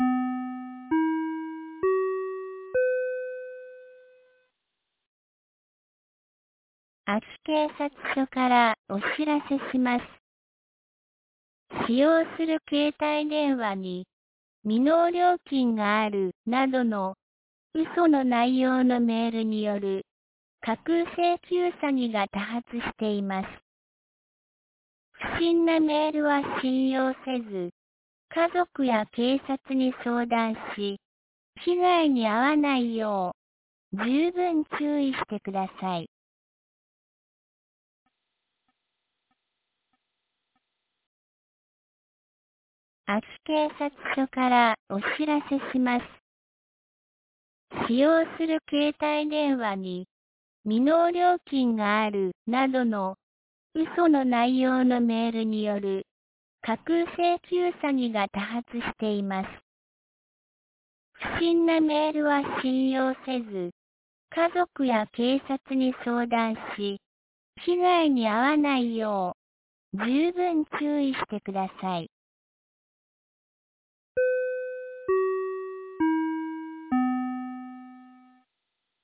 2024年06月12日 17時11分に、安芸市より全地区へ放送がありました。